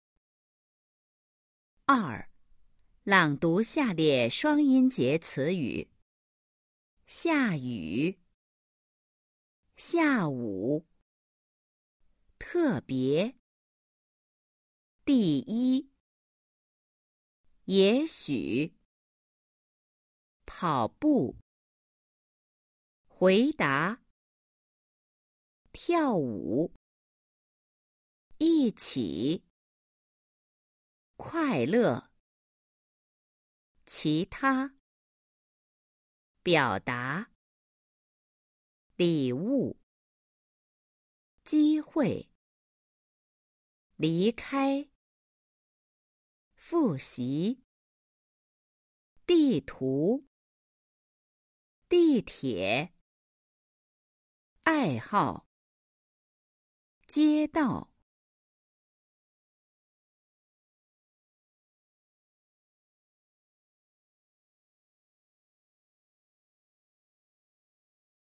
Đọc to các từ có hai âm tiết dưới đây.